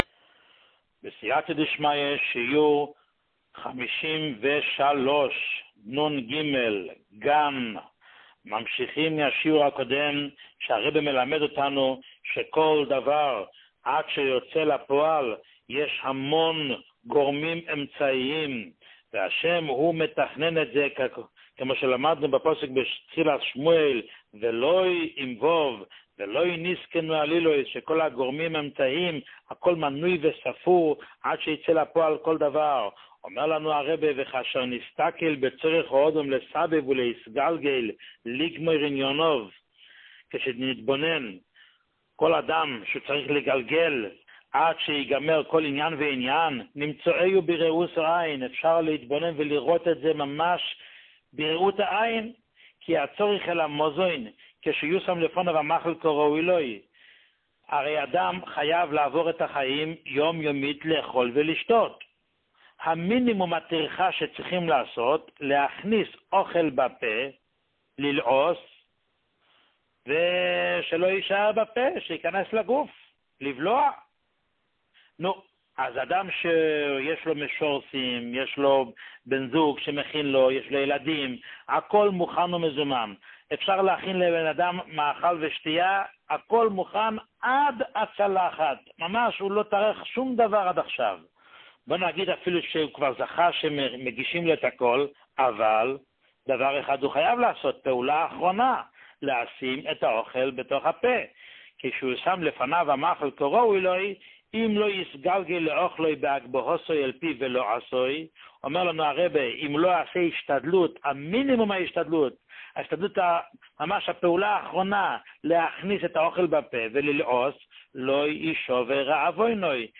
שיעורים מיוחדים
שיעור 53